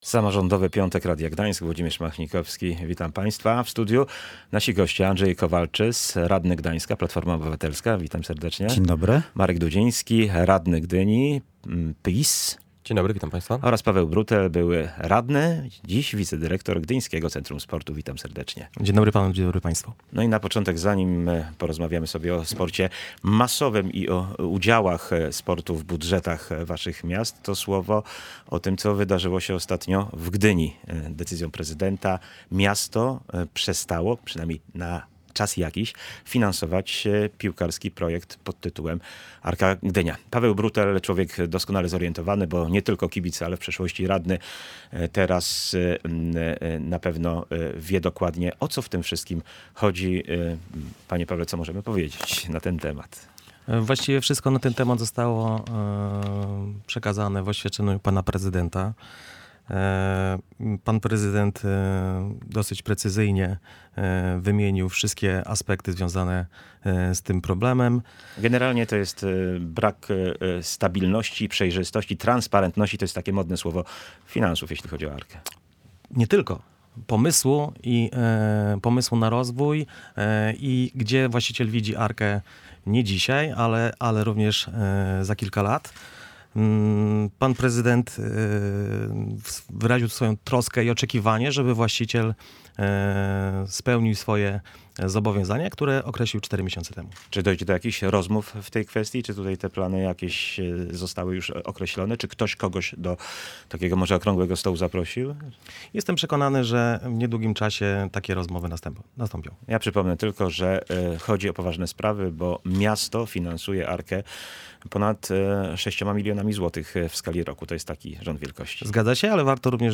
Czy promocja przez sport lepiej sprawdza się na poziomie wyczynowym czy aktywizacji mieszkańców? Między innymi o tym rozmawiali goście Samorządowego Piątku w Radiu Gdańsk.